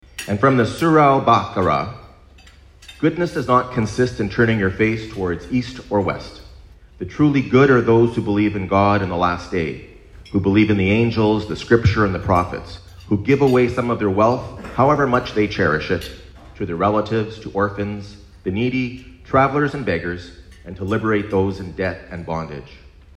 The mayor of Belleville hosted the first annual Prayer Breakfast Tuesday morning